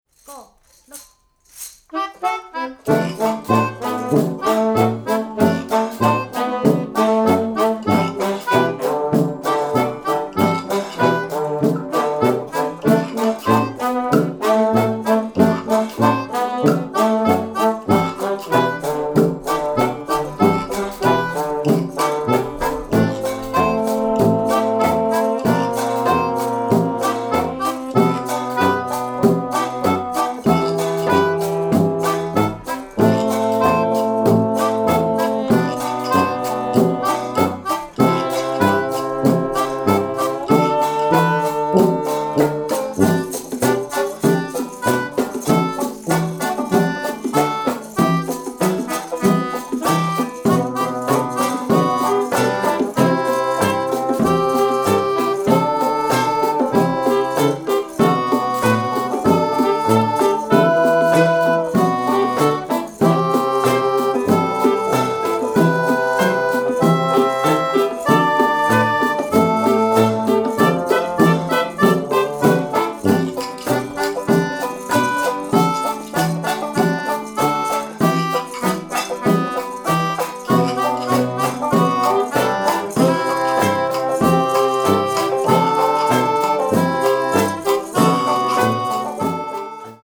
優しく楽しく叙情的なブラス・アンサンブルが◎！